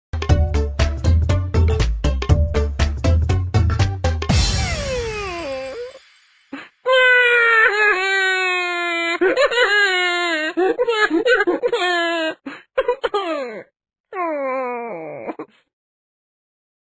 107 111 Crying sound effects free download